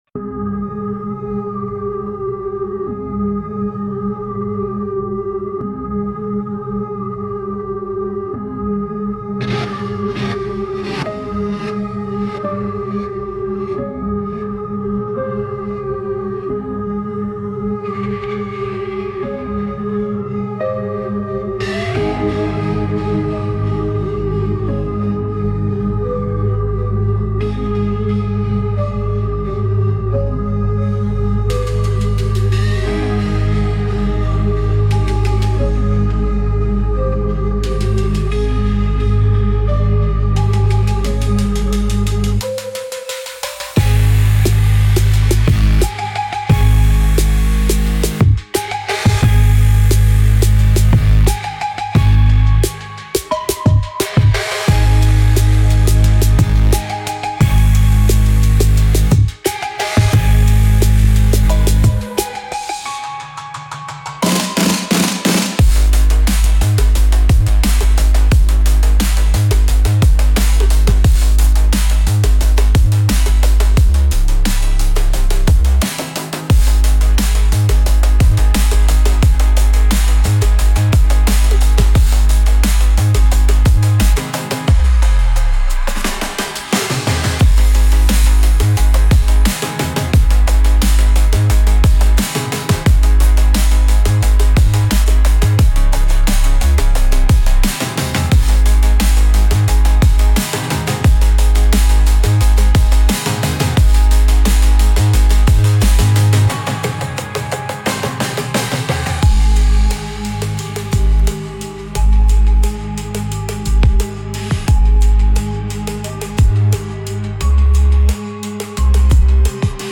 Instrumental - Real Liberty Media Dot XYZ - 4 mins.mp3